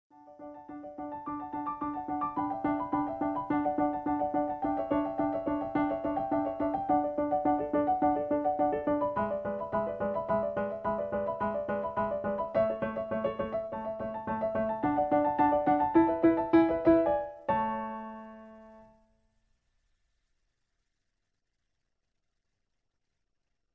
In D. Piano Accompaniment